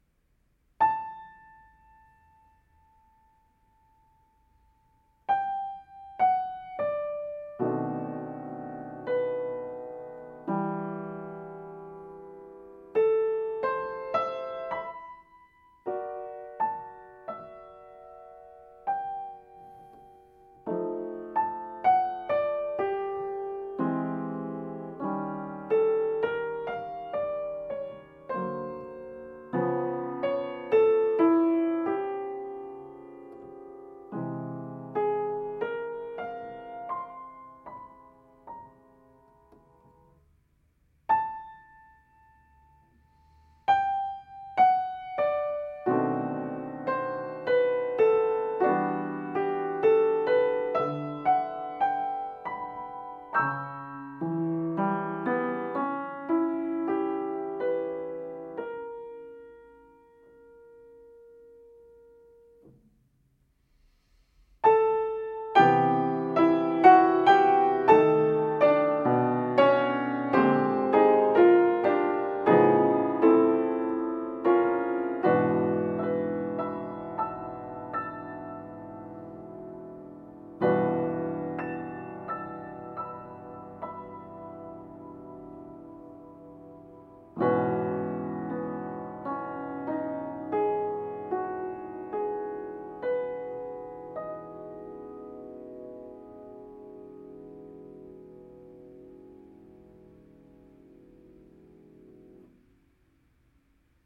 As in “Mood Indigo” or “Parker’s Mood.”Â That vein kind of.